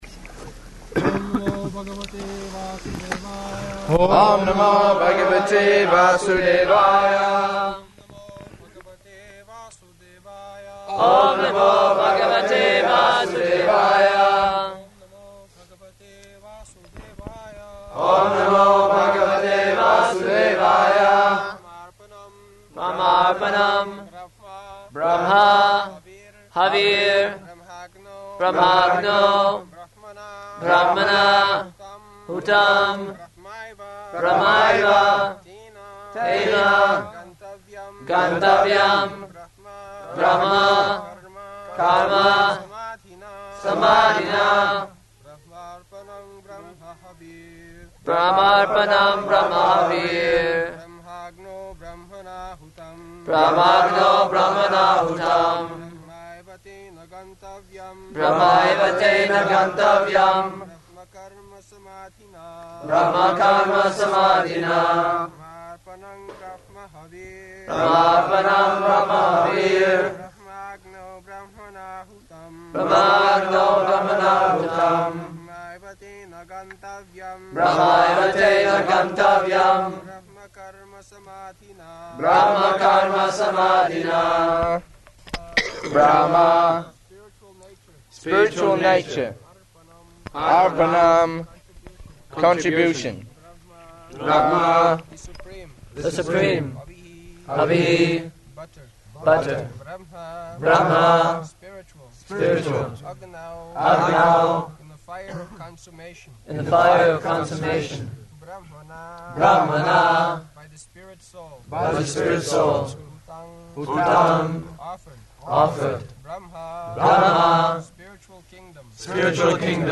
August 4th 1976 Location: New Māyāpur Audio file
[class is held outside, in garden]
[devotees repeat] [leads devotees in chanting]
Prabhupāda: [translated in French throughout] Just like the electricity energy is coming from the same source, but it is being utilized for different purposes.